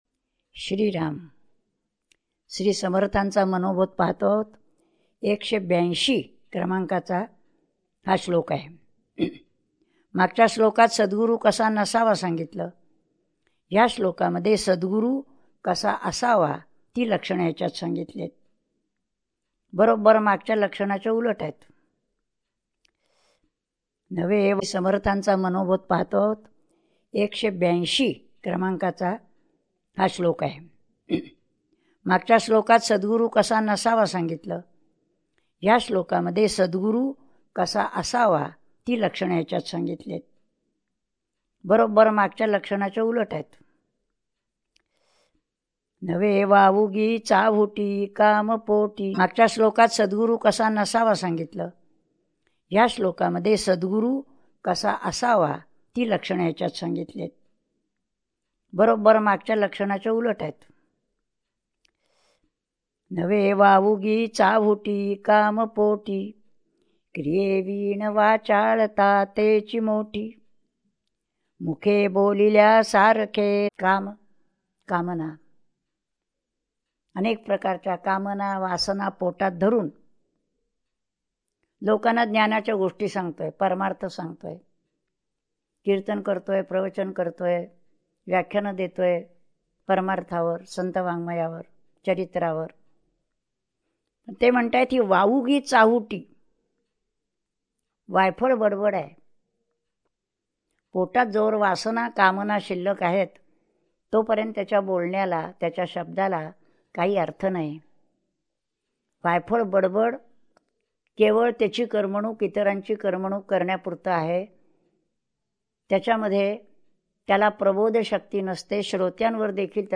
श्री मनाचे श्लोक प्रवचने श्लोक 182 # Shree Manache Shlok Pravachane Shlok 182